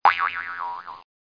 1 channel
00051_Sound_sproing.mp3